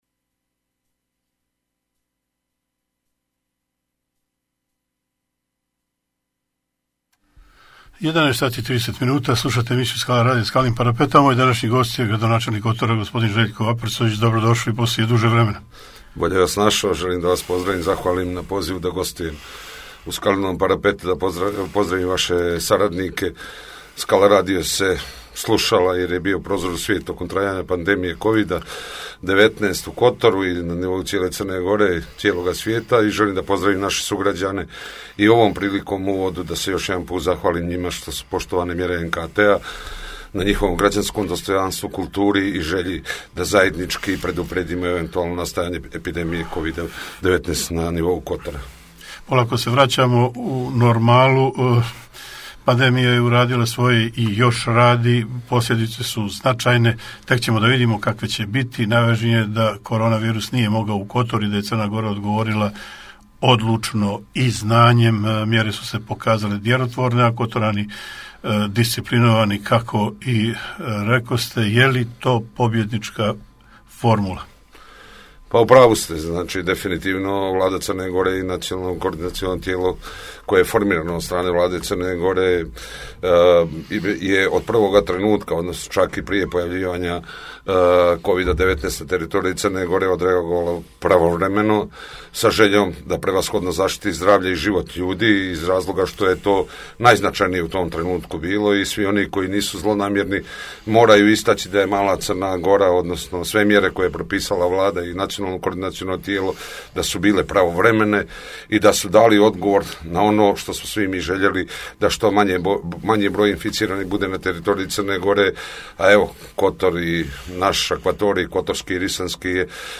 Gost emisije Željko Aprcović, gradonačelnik Kotora